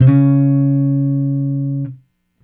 Guitar Slid Octave 02-C#2.wav